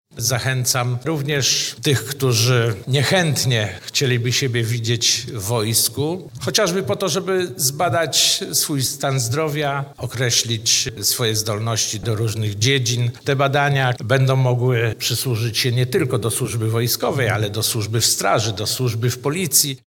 Do stawienia się przed komisje jest zobowiązanych 30 tysięcy osób z regionu. Te badania mogą się przydać także w innych zawodach – mówi wicewojewoda mazowiecki, Sylwester Dąbrowski.